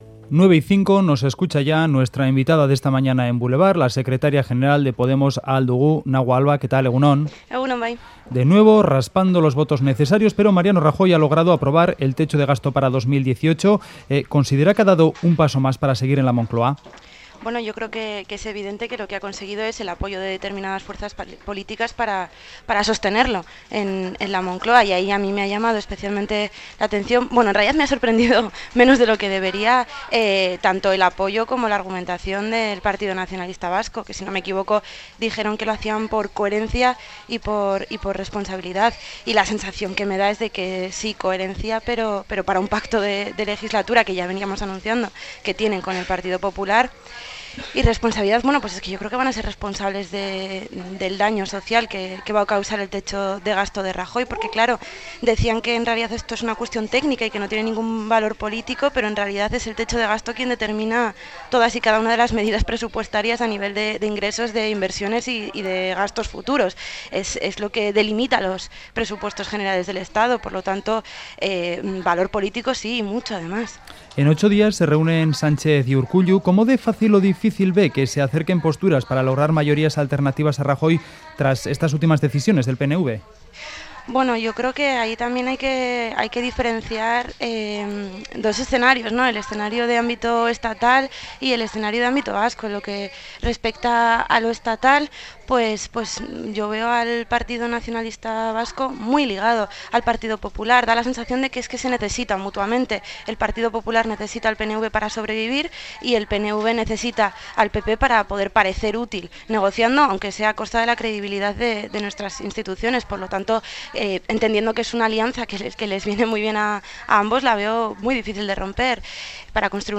Entrevistada en Boulevard, Alba ha reflexionado sobre la necesidad de debatir la estrategia vasca para el sector "ante el goteo" de industrias que entran en situación de crisis